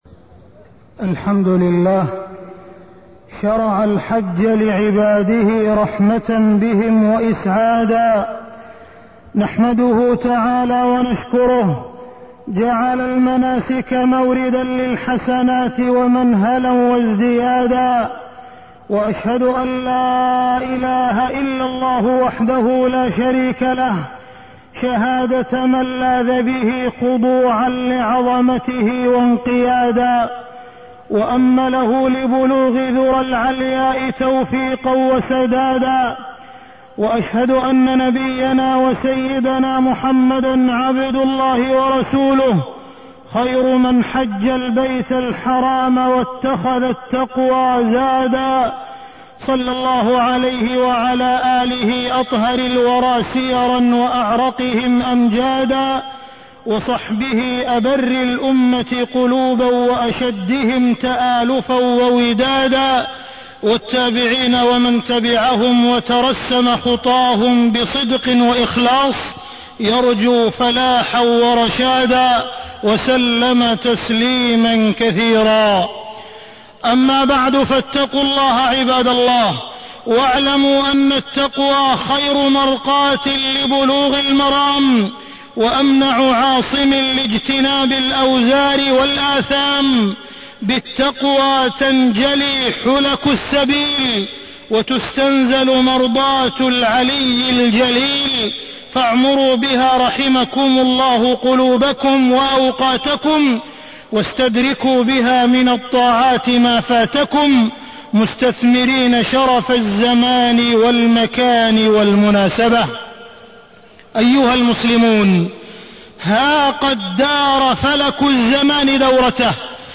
تاريخ النشر ١ ذو الحجة ١٤٢٤ هـ المكان: المسجد الحرام الشيخ: معالي الشيخ أ.د. عبدالرحمن بن عبدالعزيز السديس معالي الشيخ أ.د. عبدالرحمن بن عبدالعزيز السديس وأتموا الحج والعمرة لله